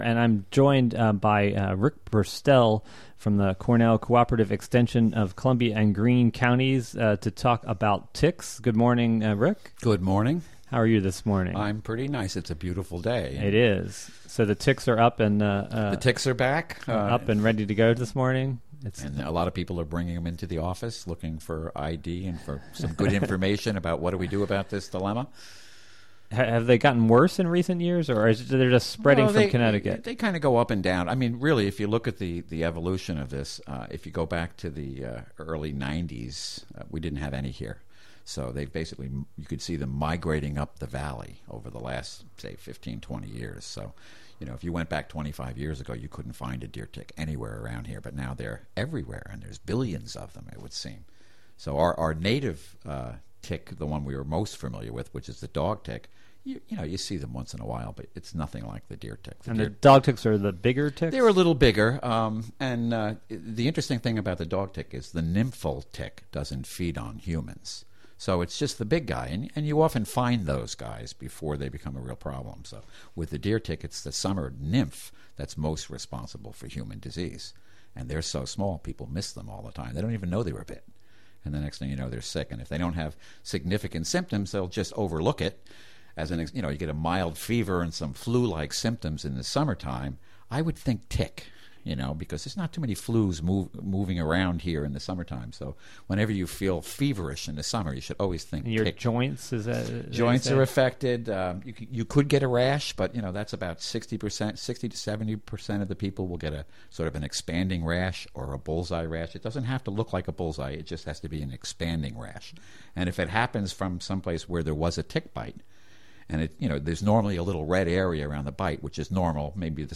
10am The WGXC Morning Show is a radio magazine show fea...